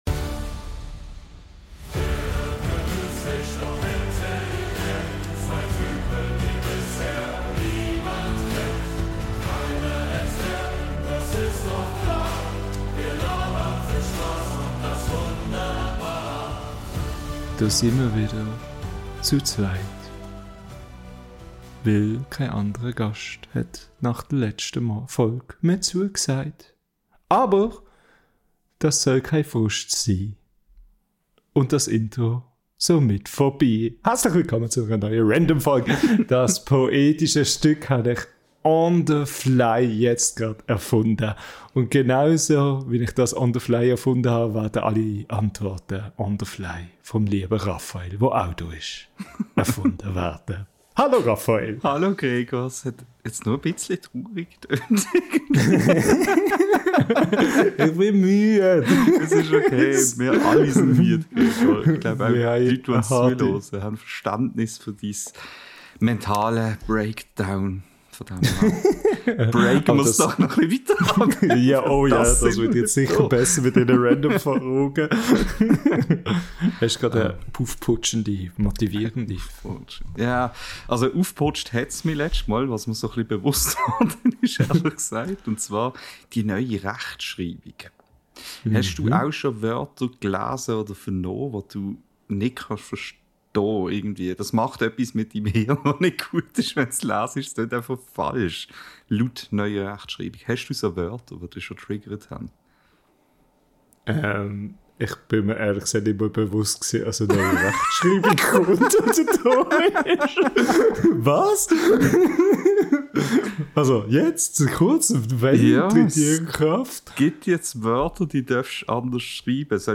Diese Woche sind wir wieder zu zweit und komplett unkontrolliert unterwegs.